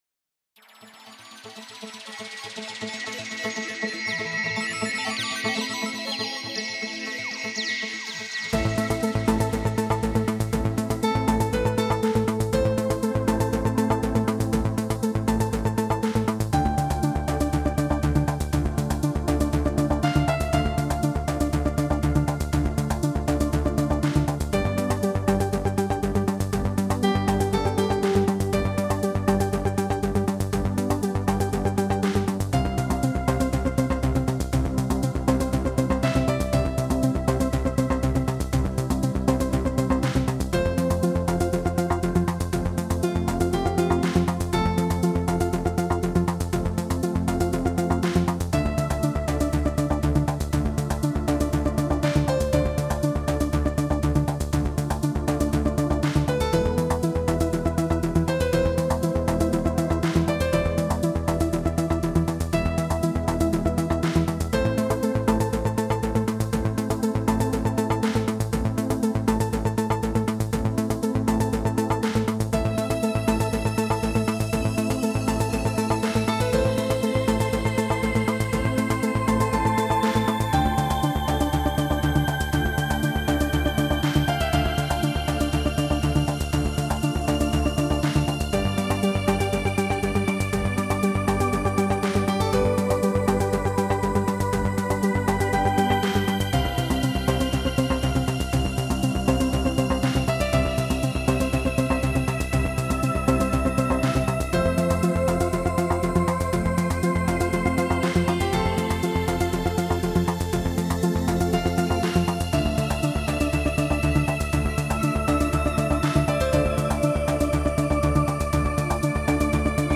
Музыка для медитации и релаксации